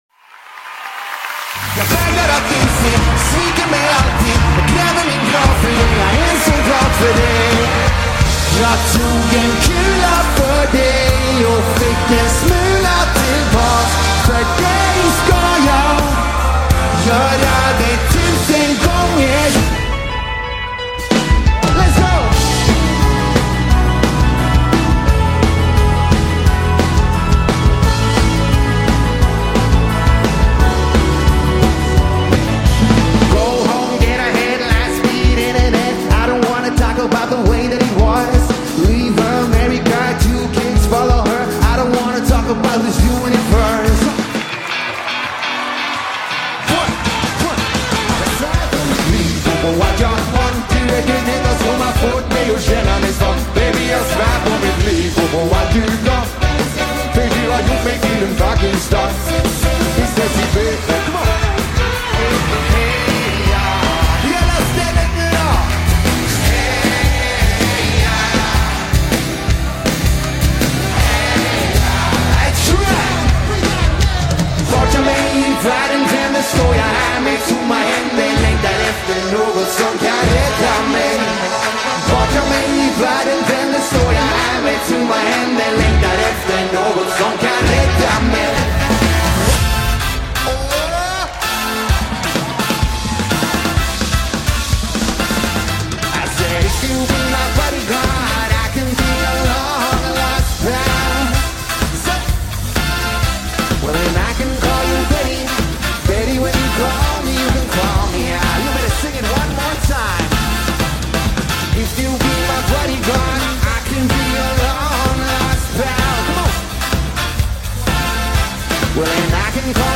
Jättebra band med blandad popmusik.
Ett modernt partyband som sticker ut ur mängden!
• Coverband